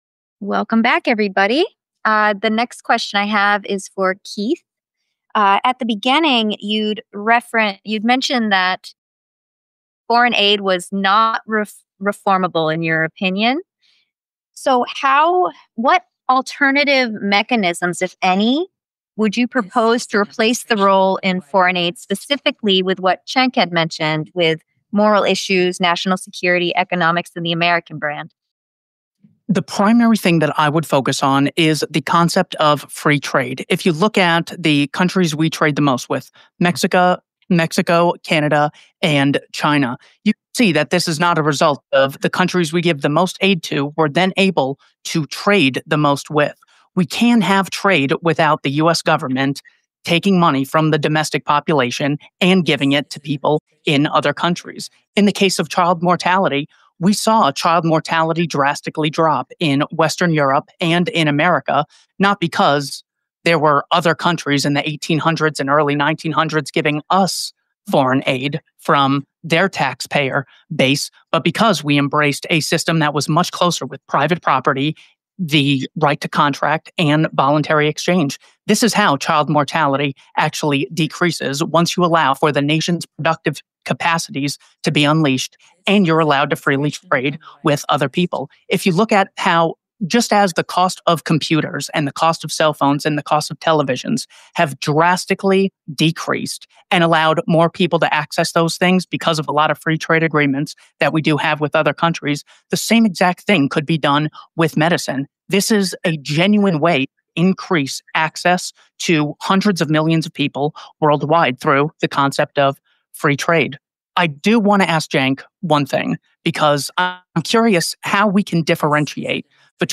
Debate: End All Foreign Aid?